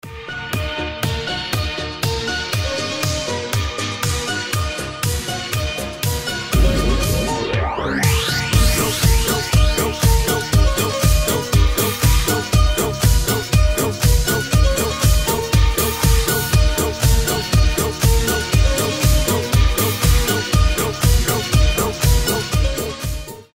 Рэп рингтоны
Mashup